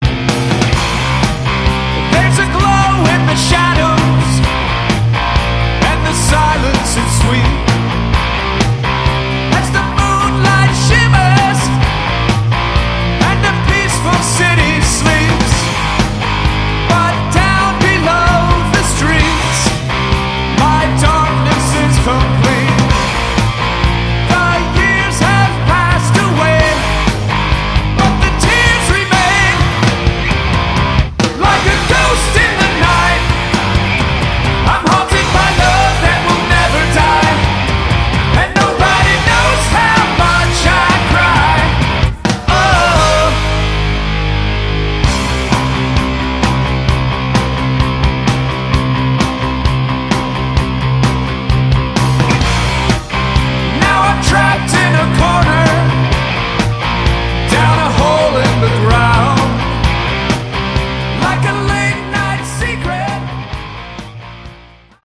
Category: Hard Rock
vocals, all instruments
Bass
Drums
Guitars